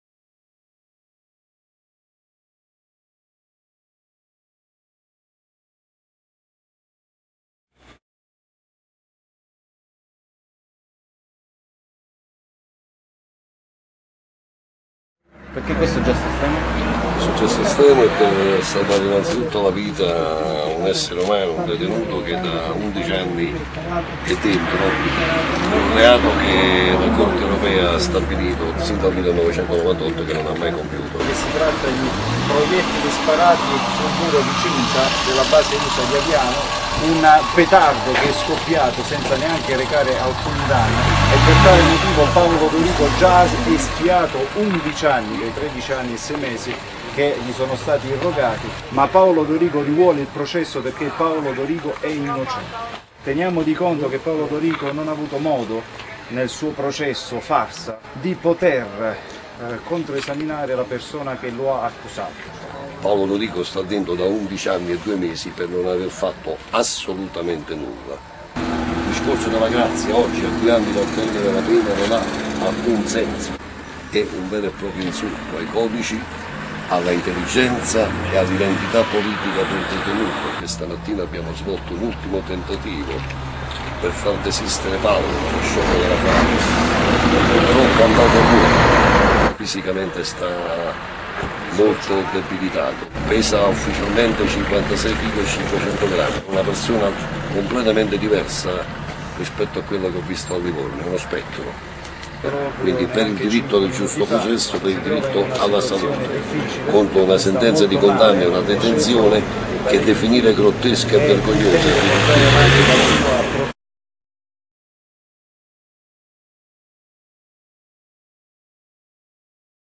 intervista agli avvocati incatenati fuori dal carcere di Maiano